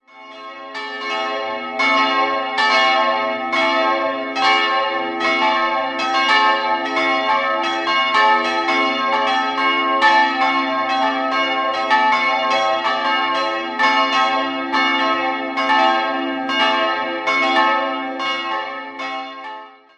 3-stimmiges A-Dur-Geläute: a'-cis''-e'' Alle drei Glocken wurden in Regensburg gegossen: Die große Glocke 1847 von Josef Anton Spannagl, die mittlere im Jahr 1963 von Georg Hofweber und die kleine 1926 von Karl Hamm.